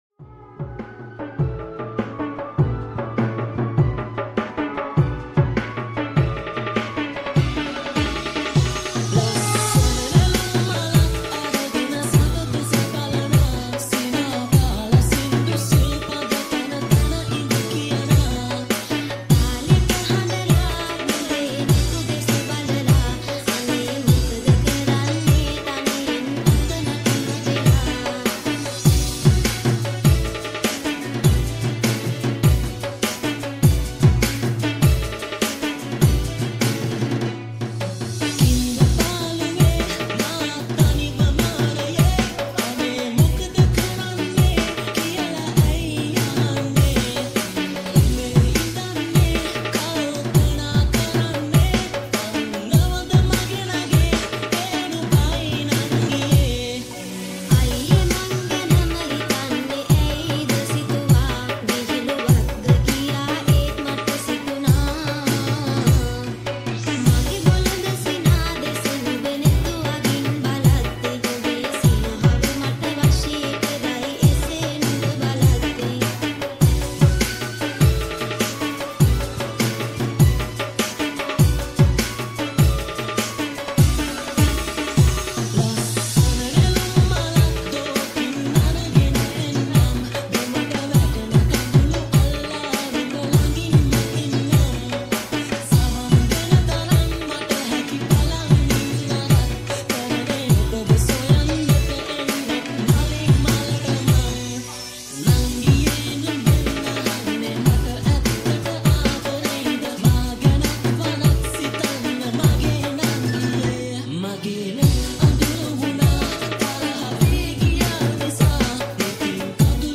Sri Lankan Traditional Remix